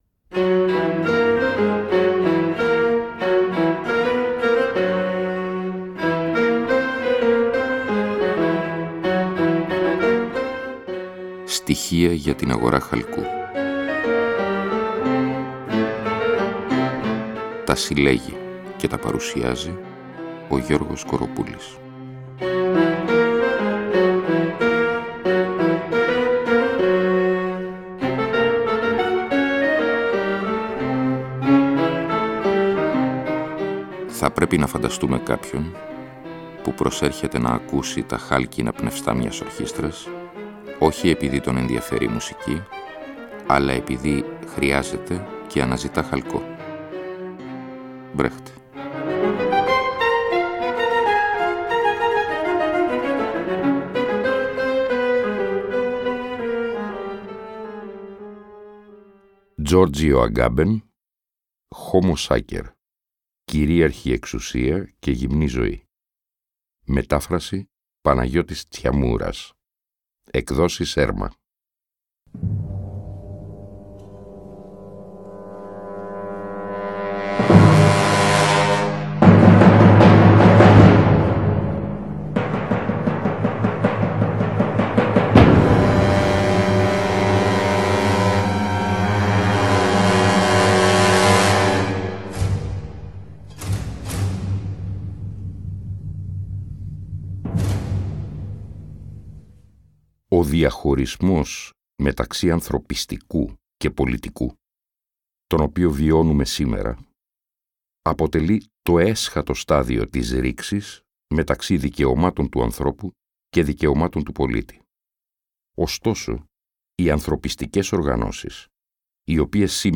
Εκπομπή λόγου. Ακούγονται, ερμηνεύονται και συγκρίνονται με απροσδόκητους τρόπους κείμενα λογοτεχνίας, φιλοσοφίας, δοκίμια κ.λπ. Η διαπλοκή του λόγου και της μουσικής αποτελεί καθ εαυτήν σχόλιο, είναι συνεπώς ουσιώδης.